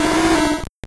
Cri de Maraiste dans Pokémon Or et Argent.